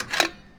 FuelLineReplace.wav